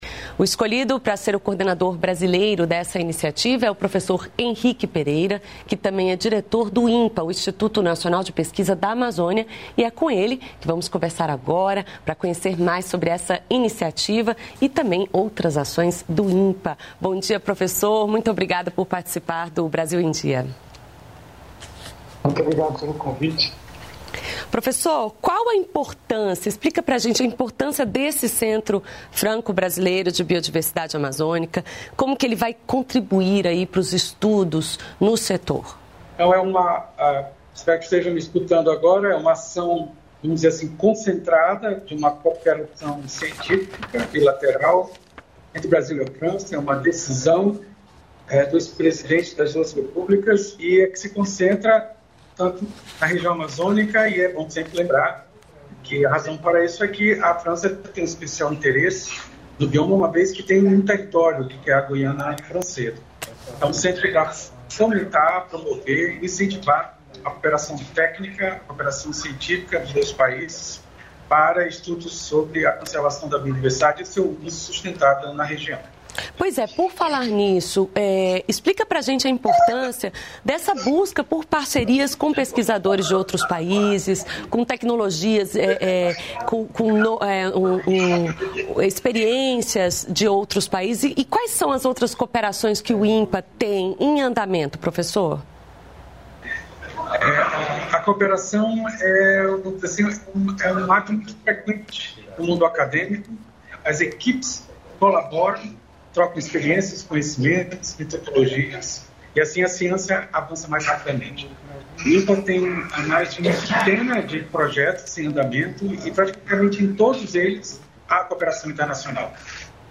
Entrevistas veiculadas no jornal Brasil em Dia, do Canal Gov, com temas factuais e de interesse do cidadão em várias áreas.